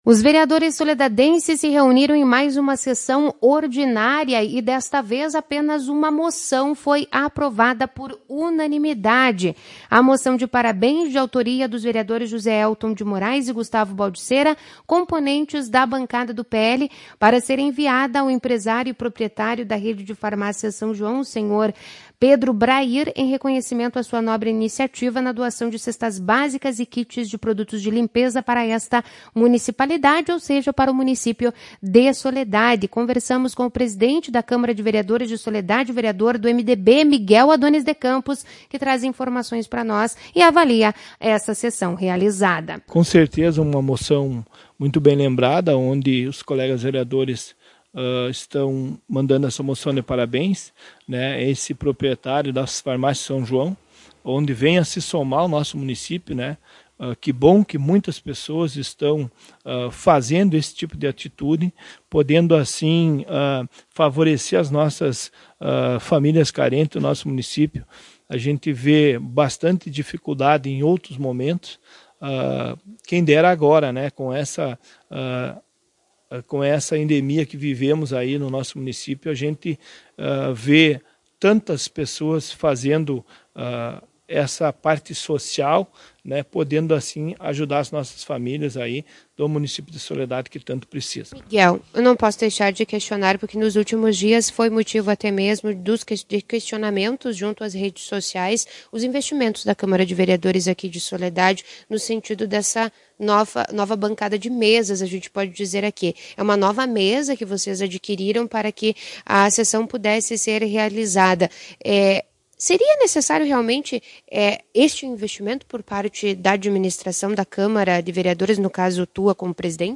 Na oportunidade o vereador presidente, Miguel Adones de Campos, MDB, concedeu entrevista para a reportagem da Tua Rádio Cristal e explicou como ocorreu a aquisição de móveis novos para a casa legislativa.
Ouça a entrevista com Miguel Adones de campos, na íntegra, no player de áudio cima.